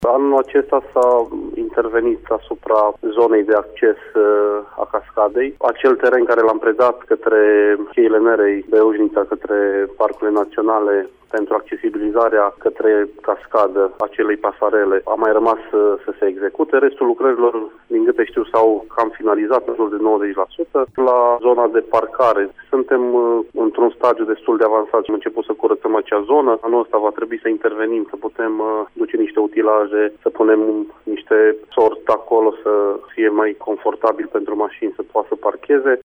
Primarul comunei Bozovici, Adrian Stoicu, spune că anul viitor va fi amplasată şi platforma de vizitare a cascadei.
Adrian-Stoicu.mp3